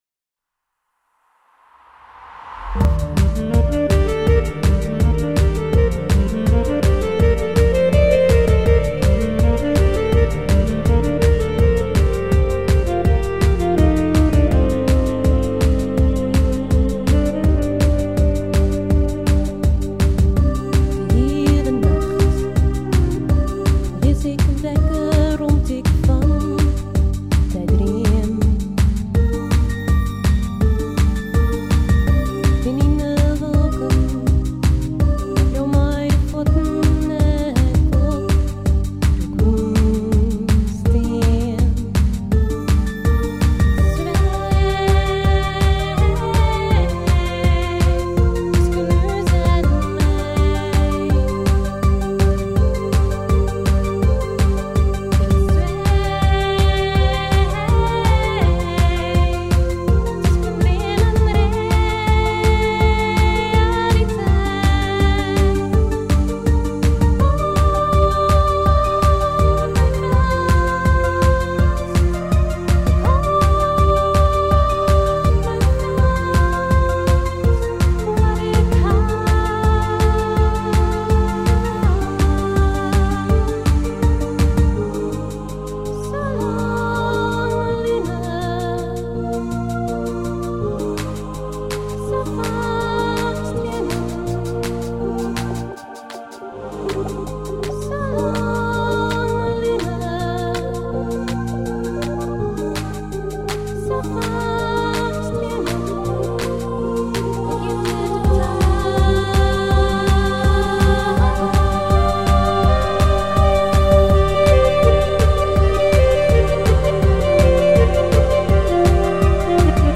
Nog een dancenummer.
Zang